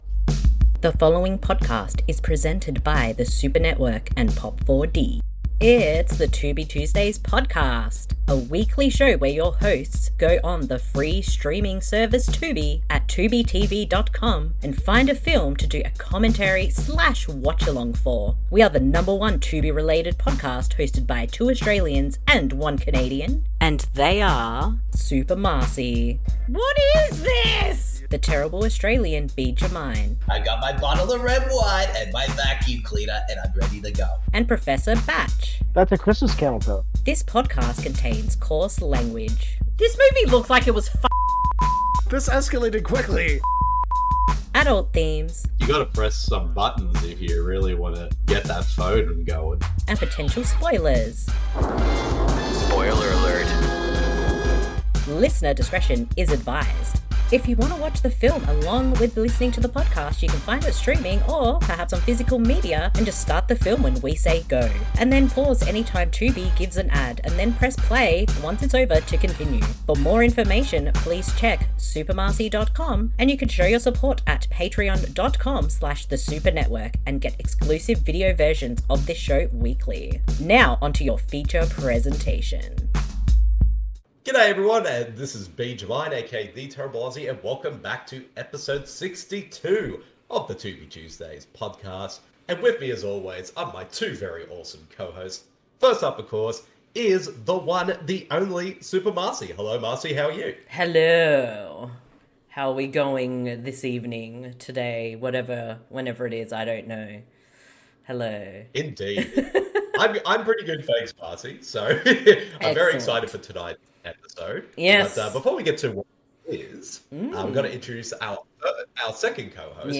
We will tell you when to press and you follow along, it is that easy! Because we have watched the films on Tubi, it is a free service and there are ads, however we will give a warning when it comes up, so you can pause the film and provide time stamps to keep in sync.